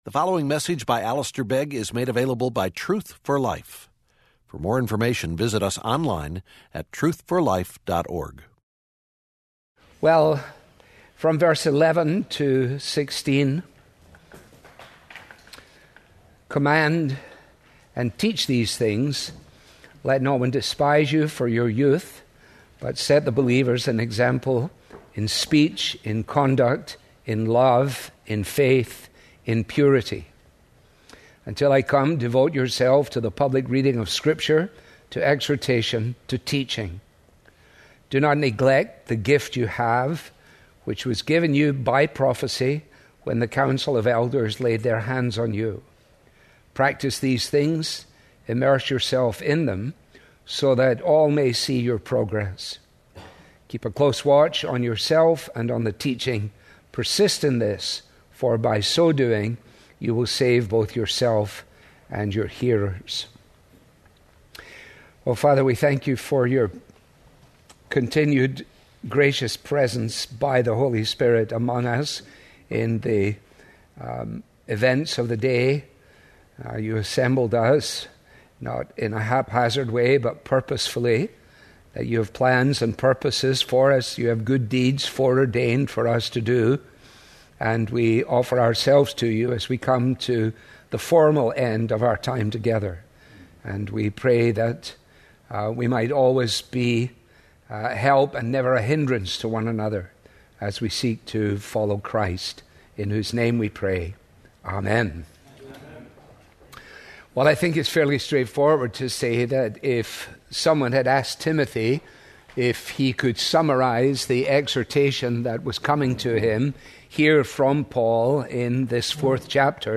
Truth For Life with Alistair Begg Sermons